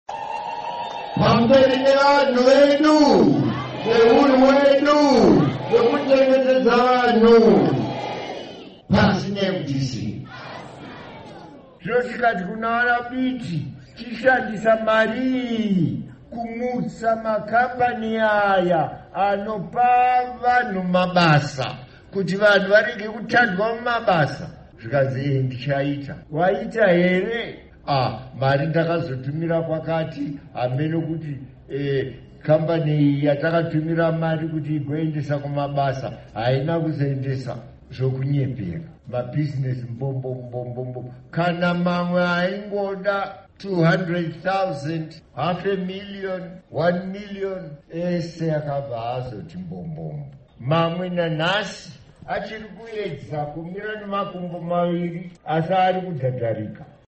Lalela Amazwi KaMongameli Robert Mugabe